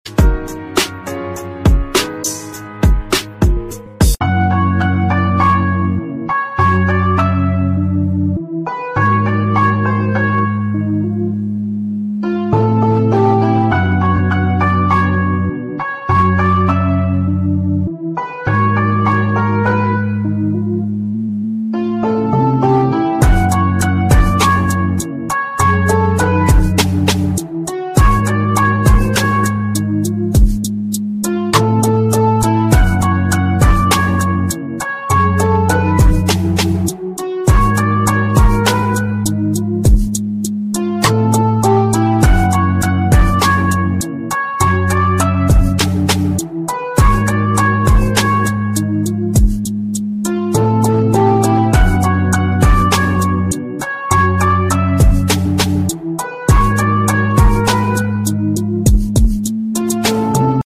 Static Nature Scene + Sounds for Relaxation